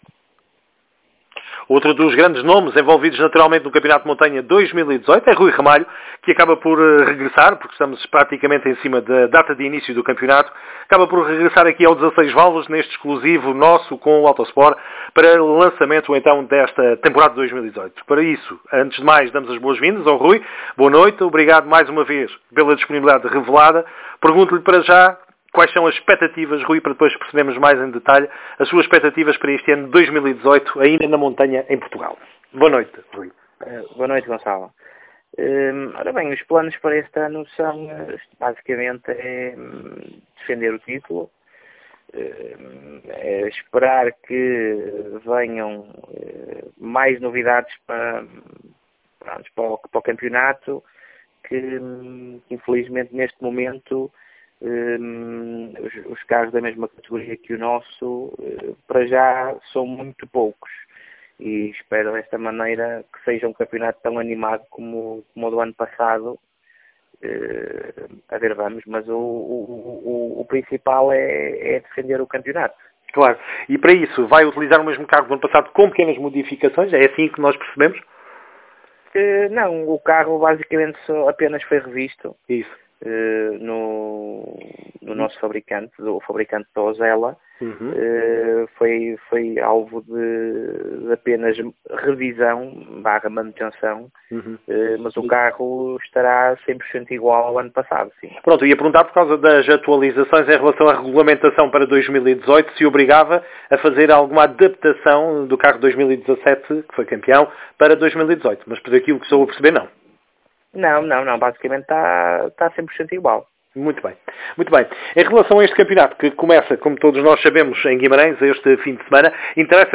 #16ValvulasEntrevista